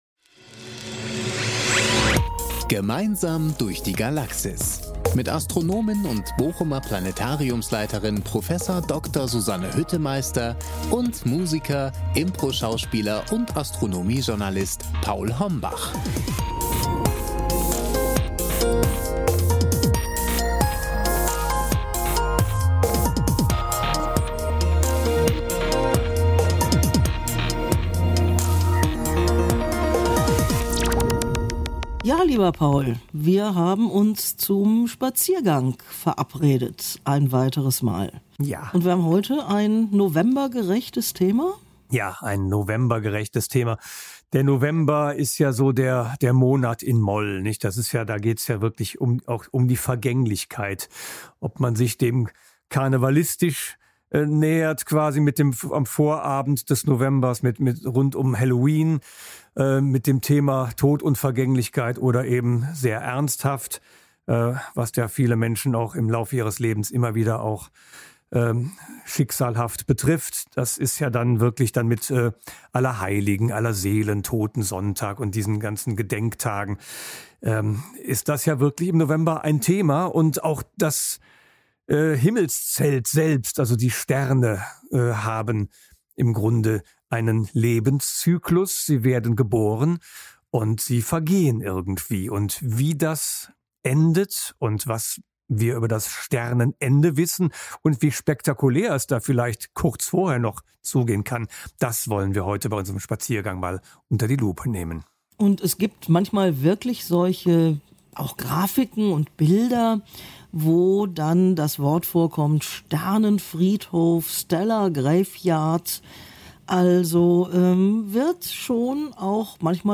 Astronomische Plaudereien aus dem Bochumer Planetarium